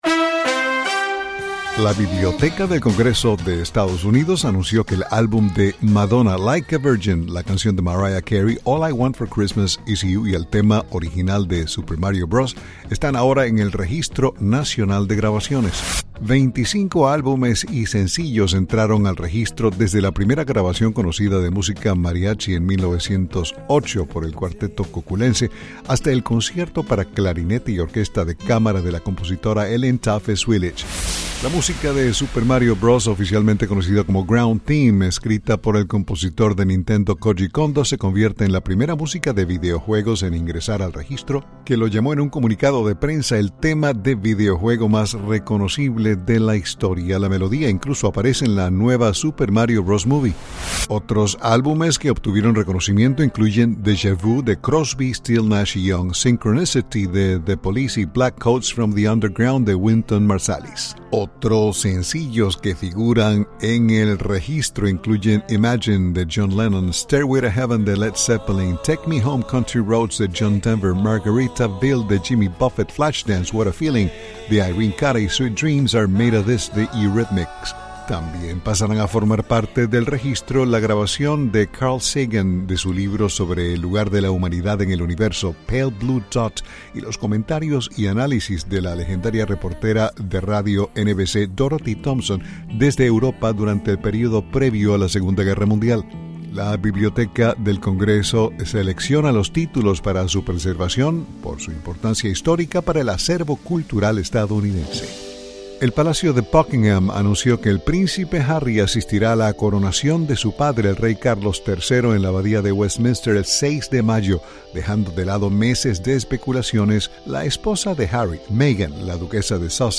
con la información del entretenimiento, desde la Voz de América, Washington.